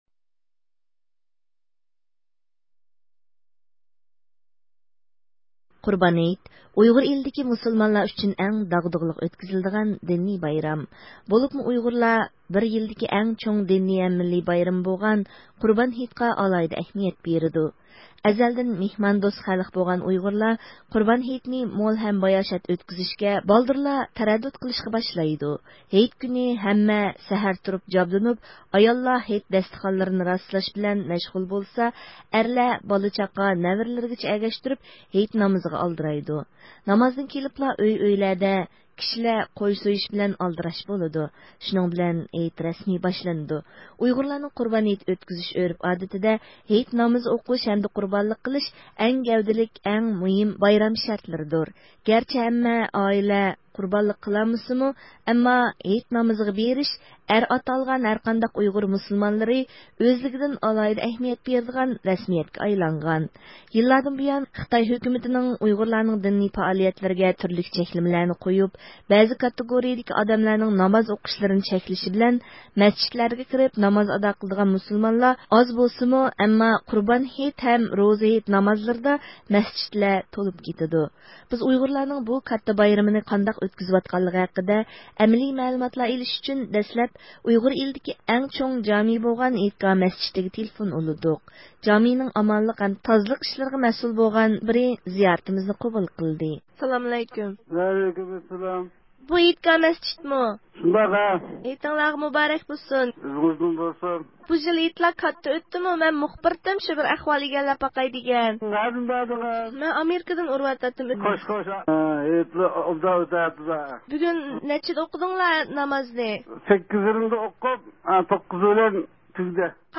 بىز ئۇيغۇرلارنىڭ بۇ قۇربان ھېيتنى ئۆزلىرىنىڭ ئارزۇسىدىكىدەك ئۆتكۈزەلىگەن ياكى ئۆتكۈزەلمىگەنلىكى ھەققىدە ئۇيغۇر ئېلىنىڭ خوتەن ھەم ئاقسۇ قاتارلىق جايلىرىغا تېلېفون قىلغىنىمىزدا، دىنىي بايرام بولغان قۇربان ھېيت،خىتاي ھۆكۈمىتىنىڭ يەنىلا ئۇيغۇرلارنىڭ دىنىي ئەركىنلىكىنى بوغۇپ، پارتىيە ئەزالىرى، ئوقۇغۇچىلار ھەم خىزمەتچىلەرگە ئوخشاش بىر قىسىم كىشىلەرنىڭ ھەتتا ھېيت نامىزىغا بېرىشنىمۇ چەكلىگەنلىكى مەلۇم بولدى.